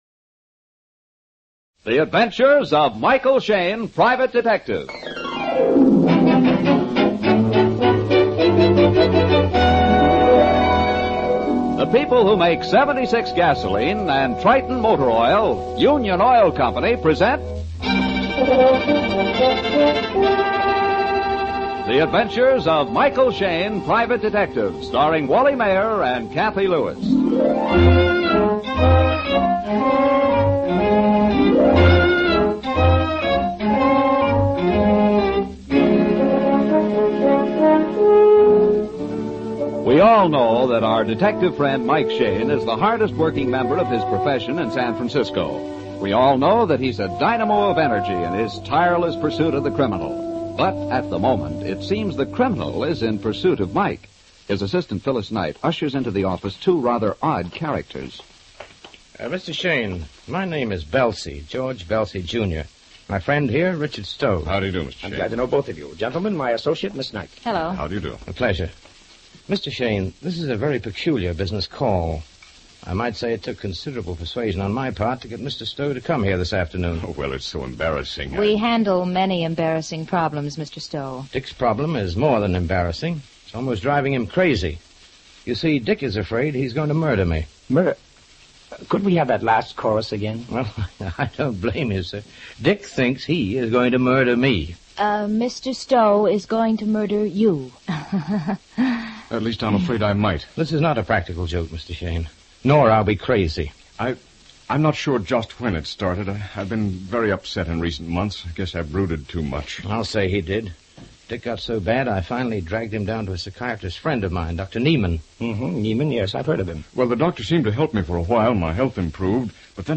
Michael Shayne 450723 Strange Business Call, Old Time Radio